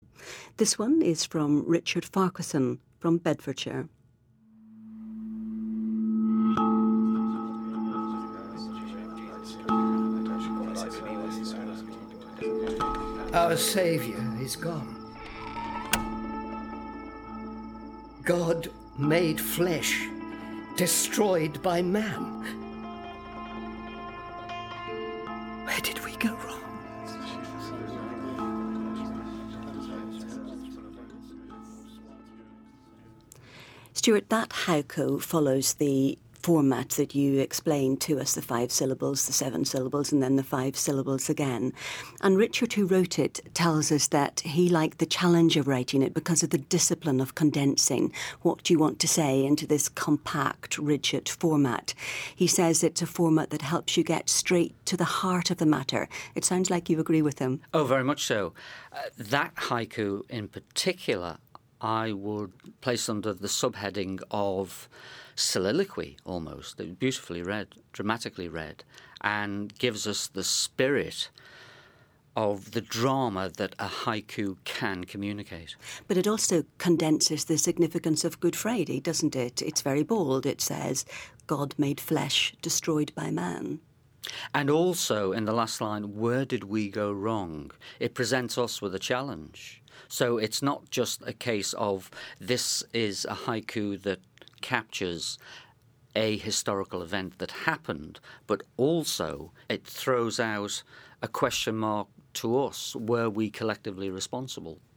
I composed a series of Haiku for Good Friday and Easter 2016. One of these was selected for inclusion and discussion in the Good Friday 2016 Podcast from Things Unseen. The reading and discussion can be heard